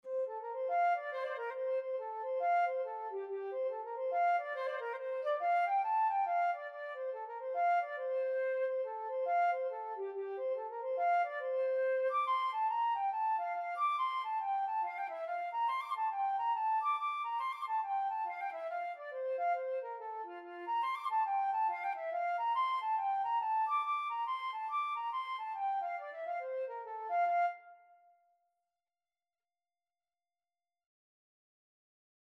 Traditional Trad. I Have No Money (Irish Folk Song) Flute version
F major (Sounding Pitch) (View more F major Music for Flute )
4/4 (View more 4/4 Music)
F5-D7
Flute  (View more Easy Flute Music)
Traditional (View more Traditional Flute Music)